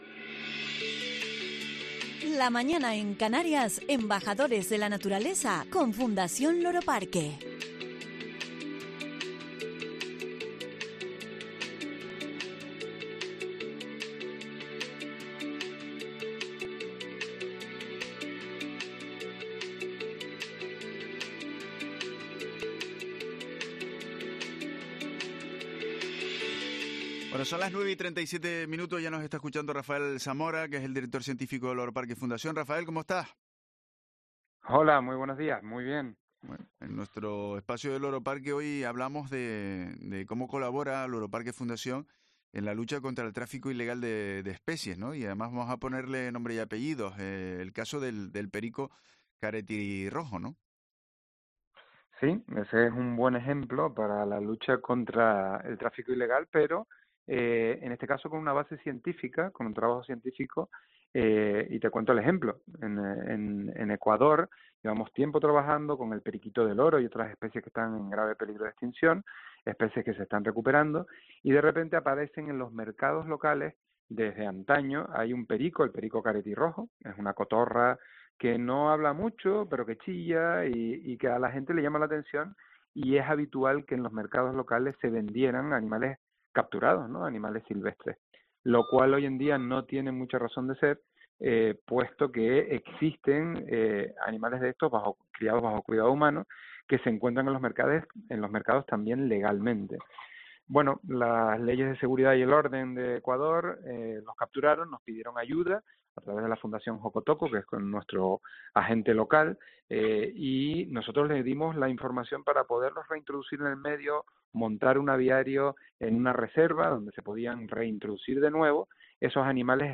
En este sentido se manifestó en Herrera en Cope Canarias